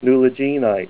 Say NULLAGINITE